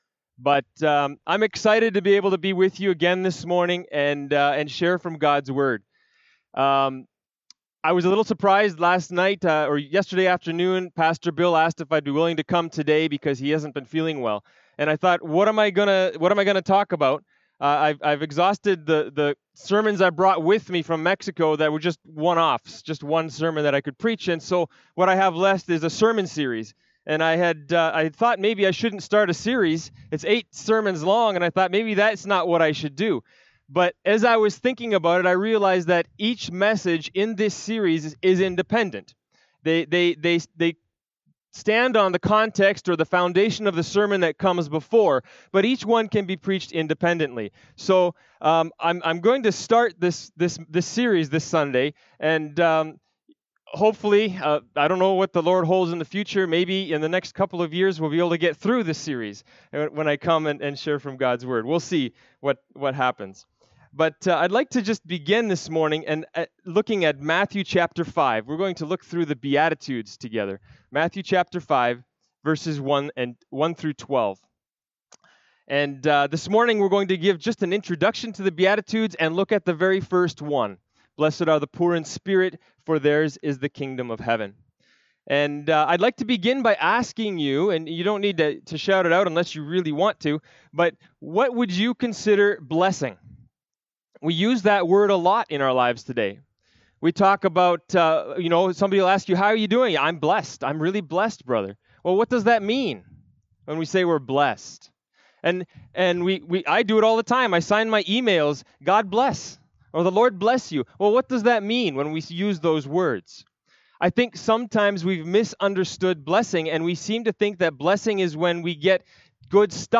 2020 Blessed Are The Poor In Spirit BACK TO SERMON LIST Preacher